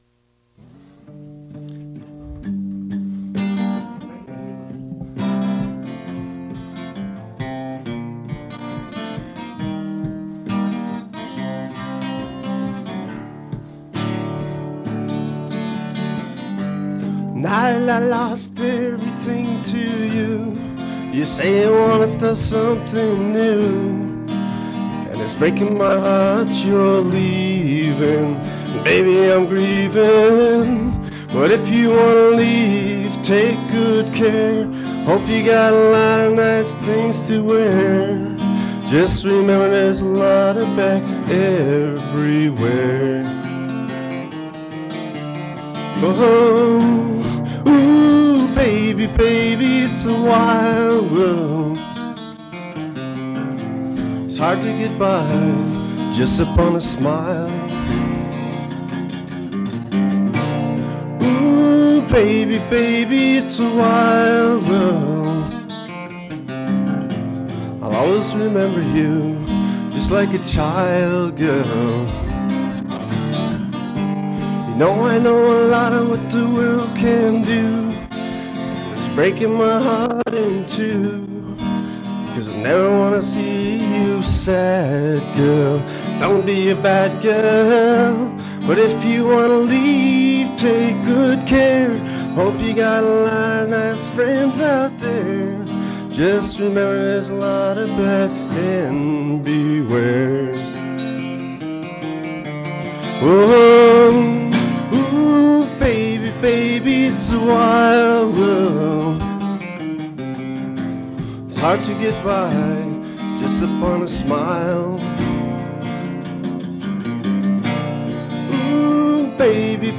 Fait partie de Acoustic reinterpretation of rock music